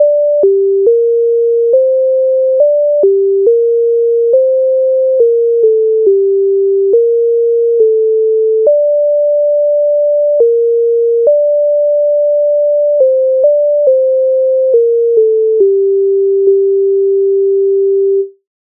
MIDI файл завантажено в тональності g-moll
Наступає чорна хмара Українська народна пісня з обробок Леонтовича с. 155
Ukrainska_narodna_pisnia_Nastupaie_chorna_khmara.mp3